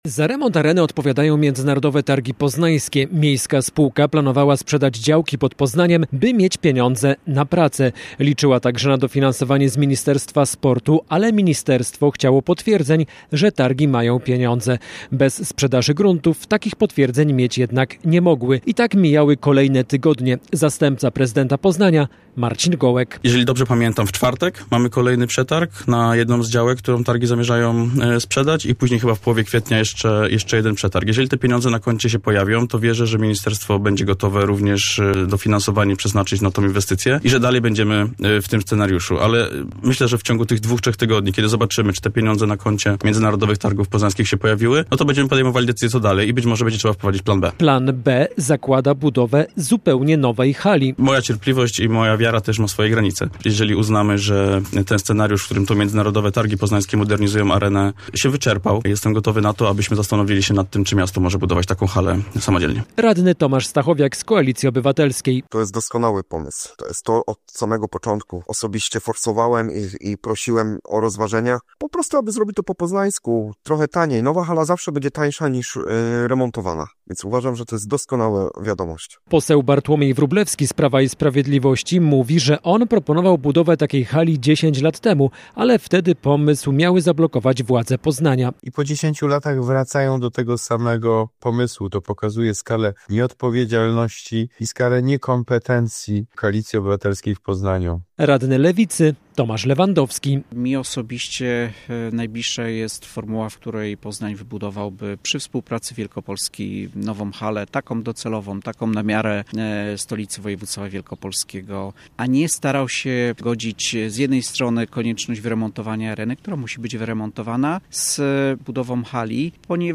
Poznań może zrezygnować z dużego remontu Areny i wybudować nową halę widowiskowo-sportową. O takim rozwiązaniu w Rozmowie Dnia Radia Poznań mówił rano zastępca prezydenta Poznania.